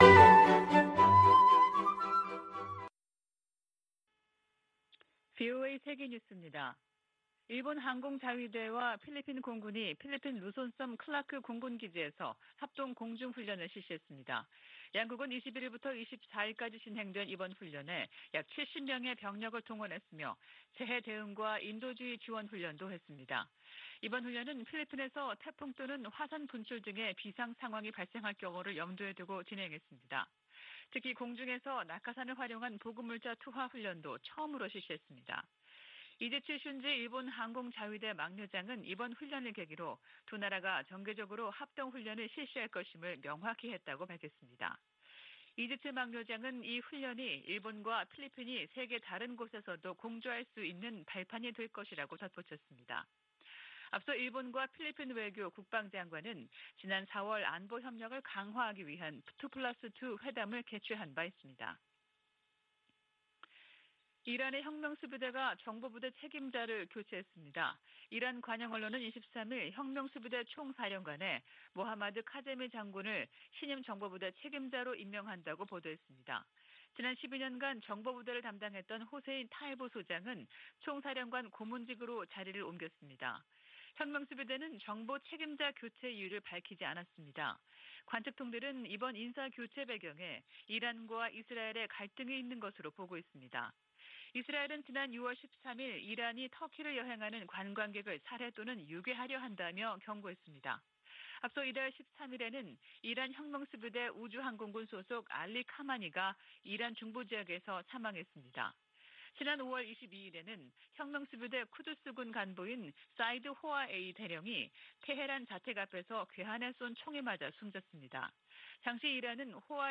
VOA 한국어 아침 뉴스 프로그램 '워싱턴 뉴스 광장' 2022년 6월 25일 방송입니다. 미 하원 군사위 의결 국방수권법안(NDAA)에 한국에 대한 미국의 확장억제 실행 방안 구체화를 요구하는 수정안이 포함됐습니다. 미국의 군사 전문가들은 북한이 최전선에 전술핵을 배치한다고 해도 정치적 의미가 클 것이라고 지적했습니다. 북한은 노동당 중앙군사위원회 확대회의에서 전쟁억제력 강화를 위한 중대 문제를 심의 승인했다고 밝혔습니다.